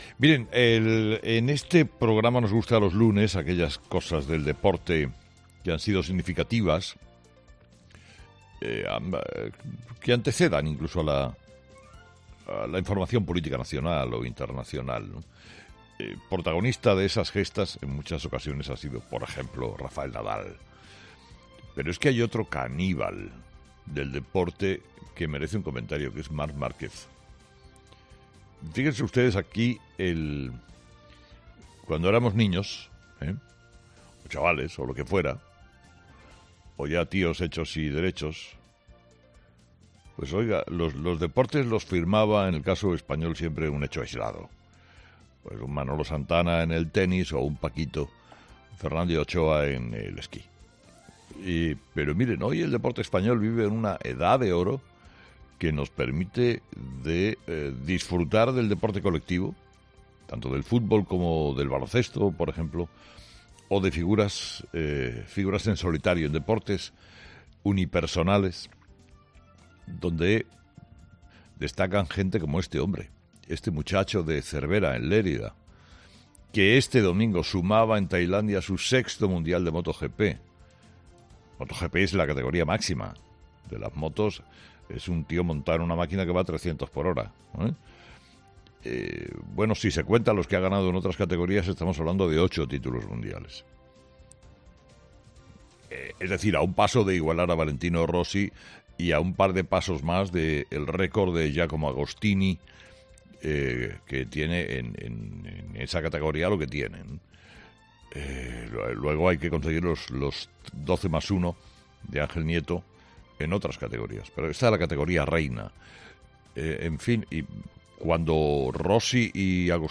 El comunicador ha comenzado la mañana del lunes poniendo el valor el hito deportivo que ha conseguido el piloto español